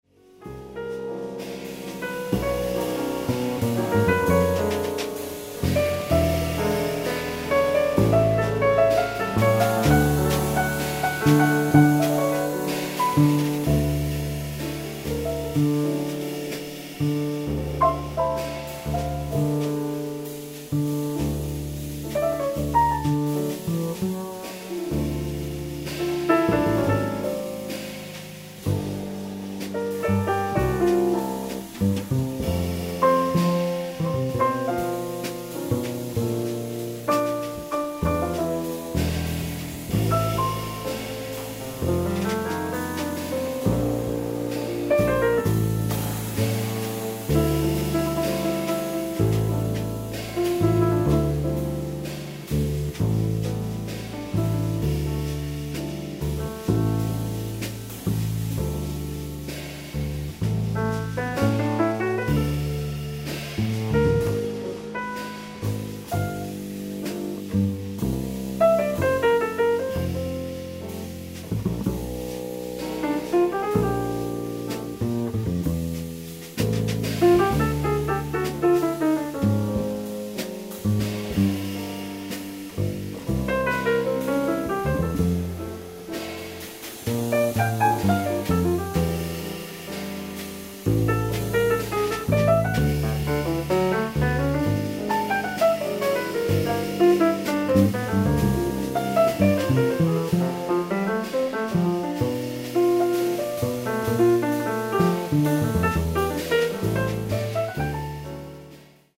ライブ・アット・マイナー・オーディトリアム、SFジャズ・センター、サンフランシスコ 04/03/2025
※試聴用に実際より音質を落としています。